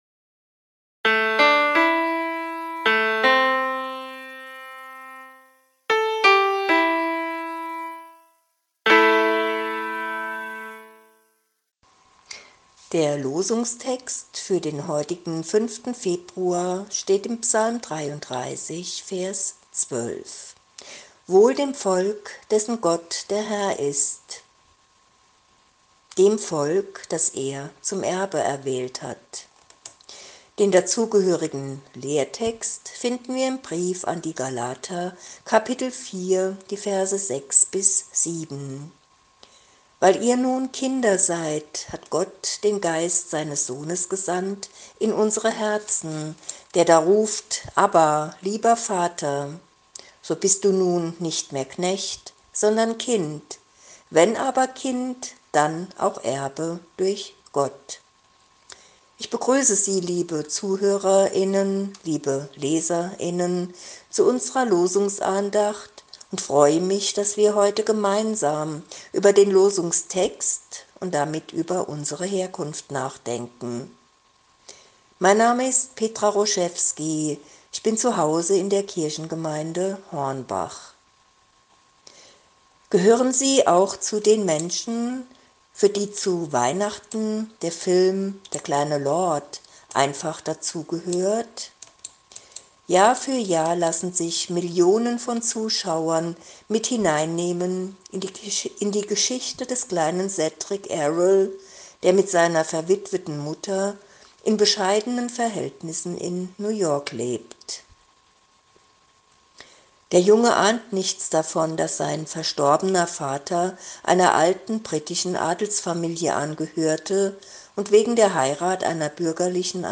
Losungsandacht für Samstag, 05.01.2022
Text und Sprecherin